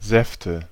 Ääntäminen
Ääntäminen Tuntematon aksentti: IPA: /ˈzɛftə/ Haettu sana löytyi näillä lähdekielillä: saksa Käännöksiä ei löytynyt valitulle kohdekielelle. Säfte on sanan Saft monikko.